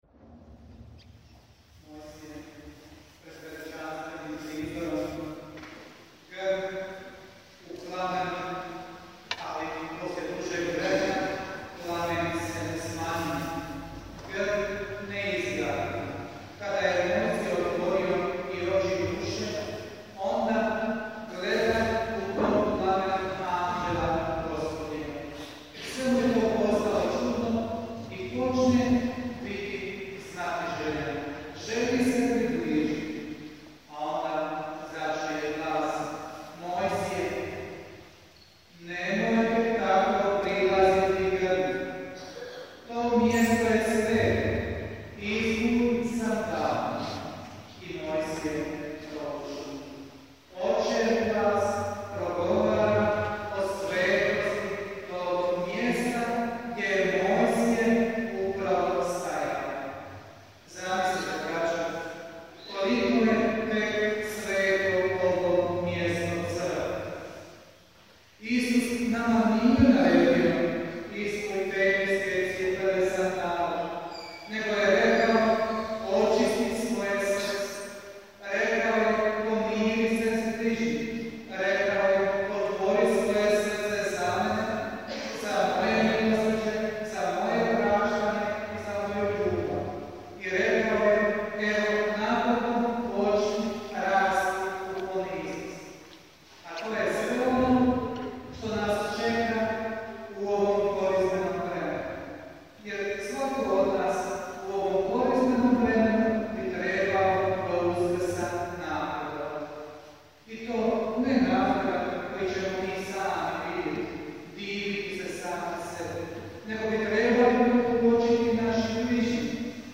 PROPOVJED